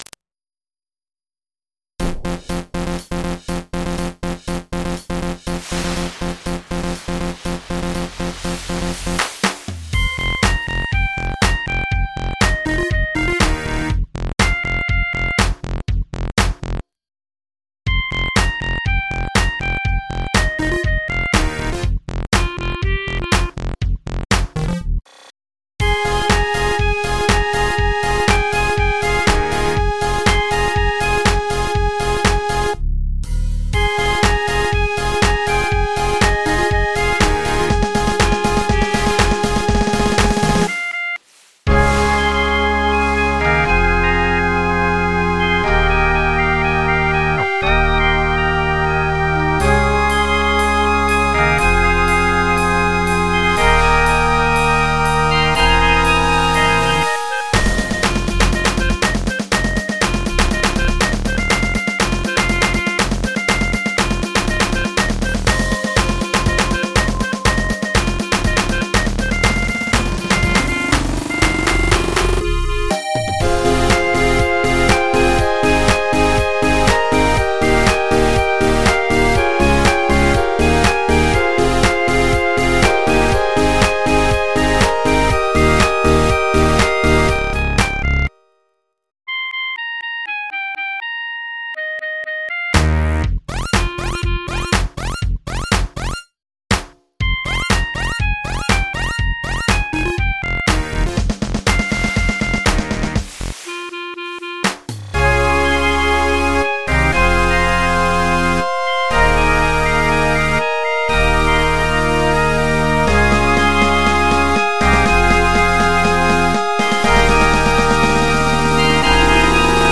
MIDI 47.06 KB MP3